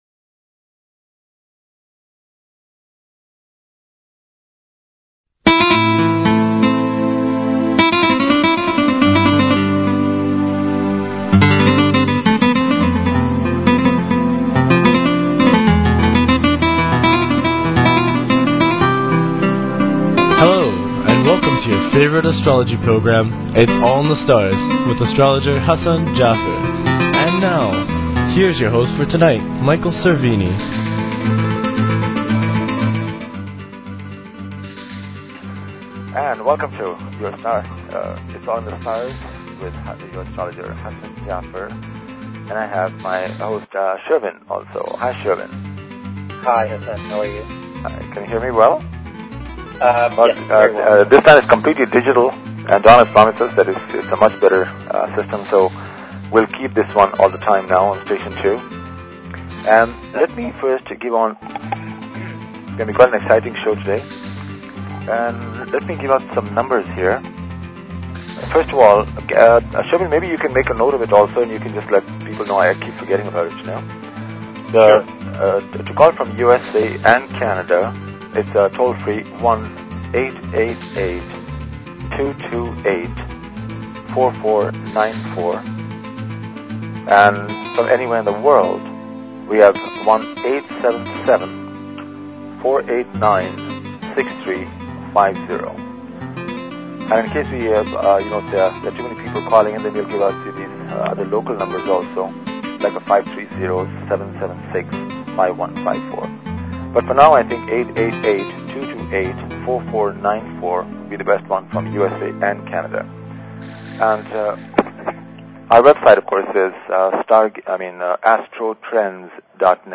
Talk Show Episode, Audio Podcast, Edge_of_Wonder_Radio and Courtesy of BBS Radio on , show guests , about , categorized as